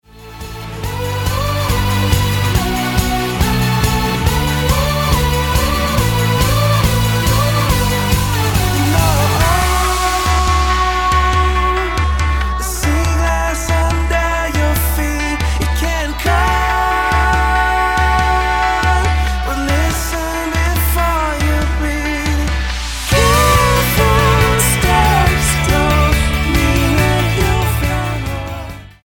thoughtful indie pop
Style: Pop